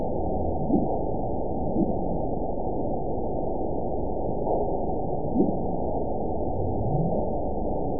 event 922407 date 12/31/24 time 07:00:38 GMT (5 months, 3 weeks ago) score 9.53 location TSS-AB02 detected by nrw target species NRW annotations +NRW Spectrogram: Frequency (kHz) vs. Time (s) audio not available .wav